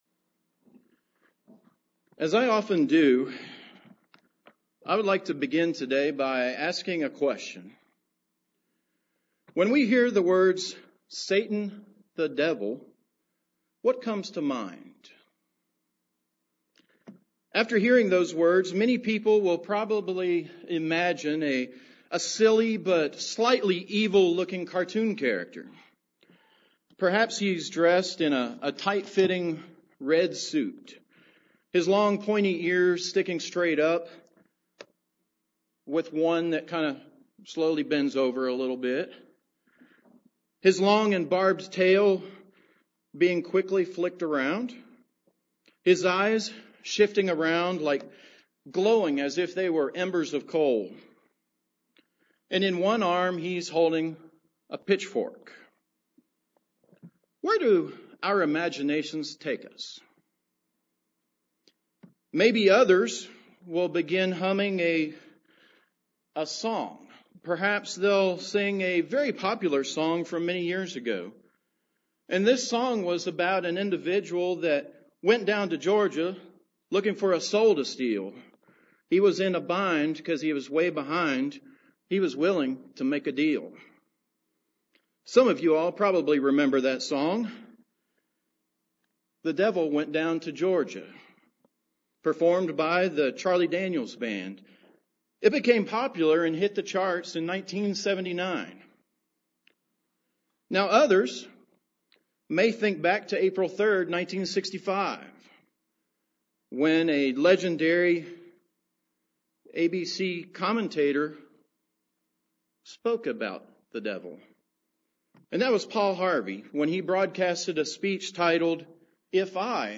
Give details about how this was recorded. Given in Charlotte, NC